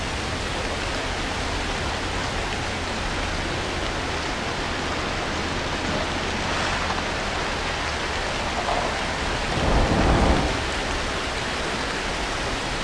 raining_loop.wav